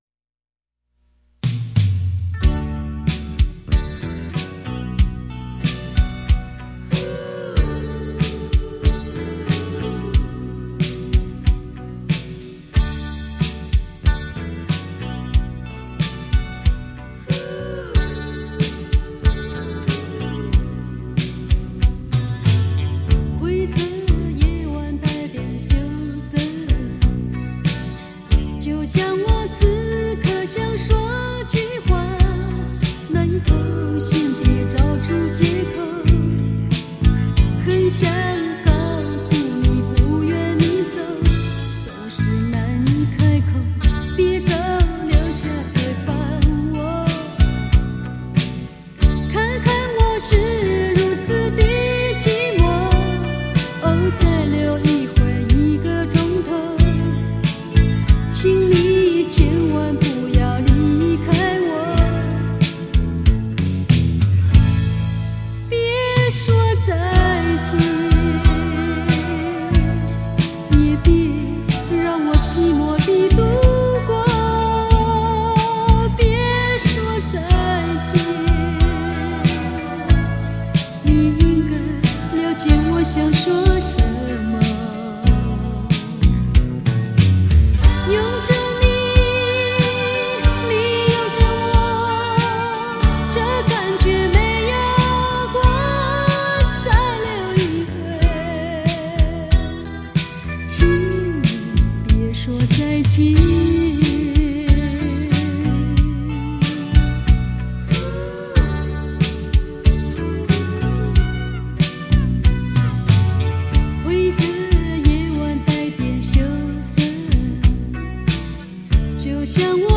界于民歌与流行风之脉动